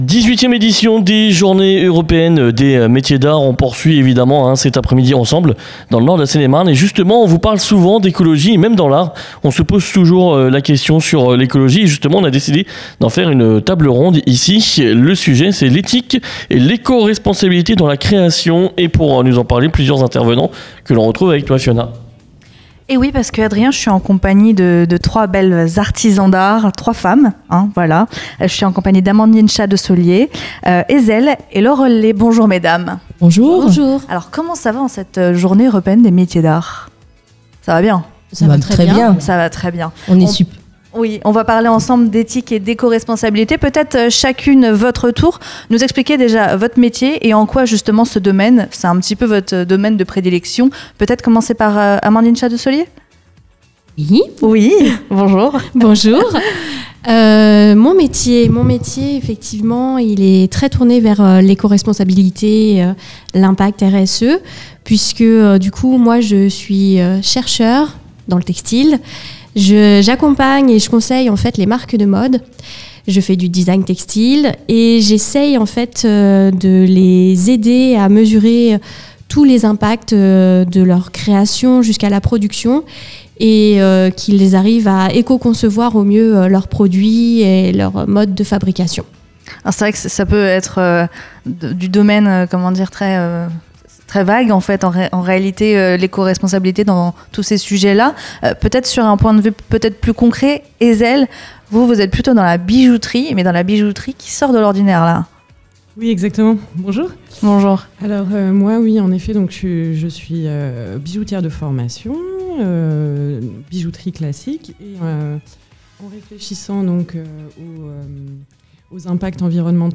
Interview Crazy Radio, le 07.04.2024
Table-ronde : L’éthique et l’éco-responsabilité dans le milieu de la création